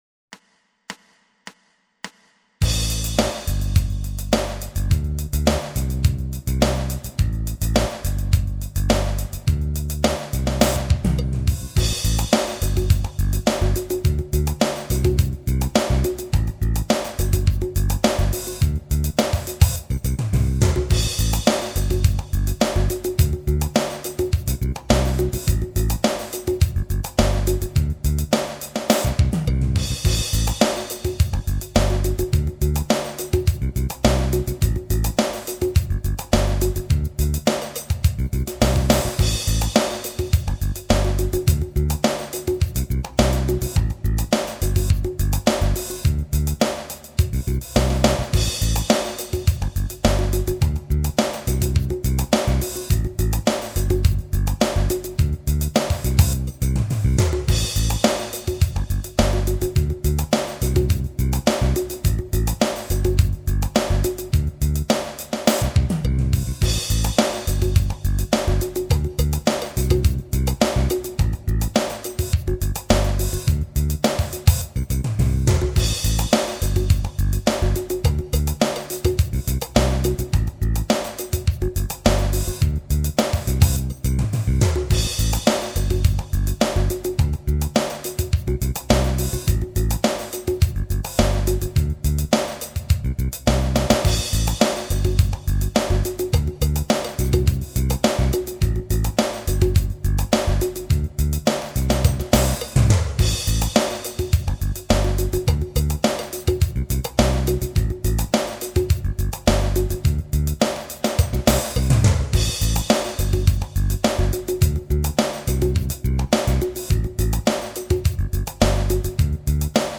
Base basso e batteria (3.521 KB)